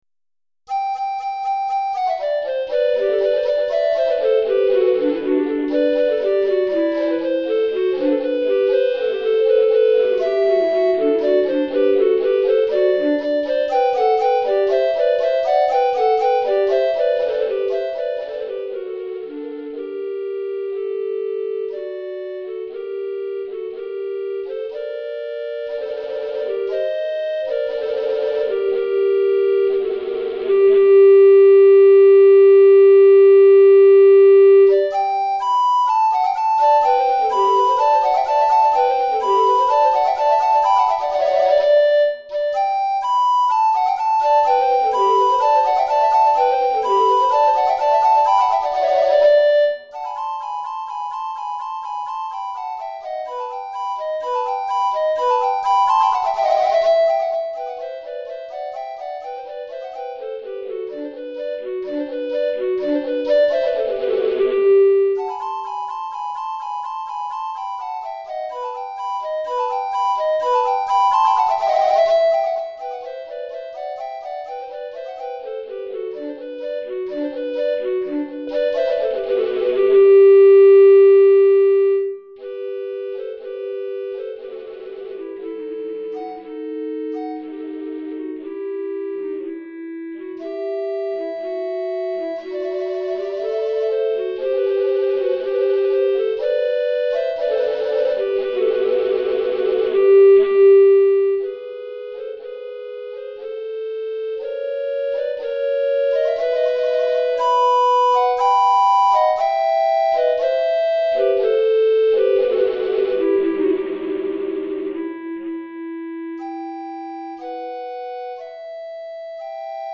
2 Flûtes à Bec Soprano